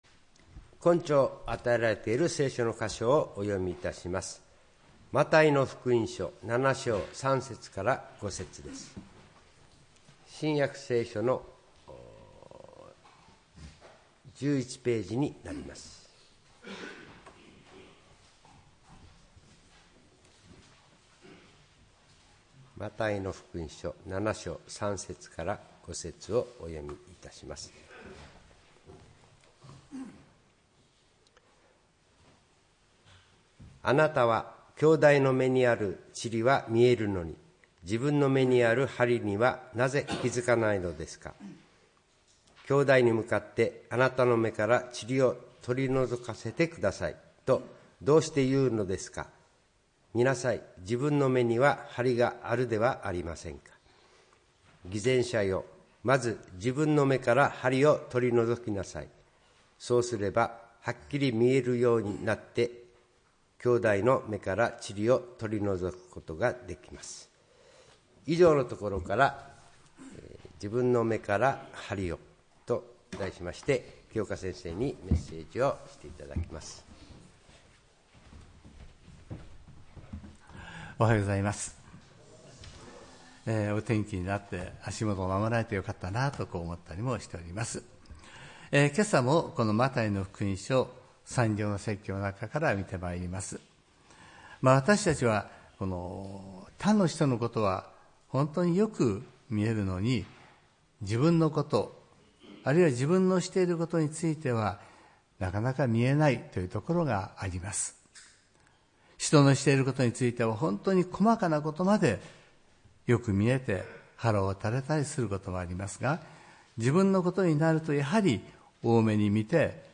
礼拝メッセージ「自分の目から梁を」(６月１日）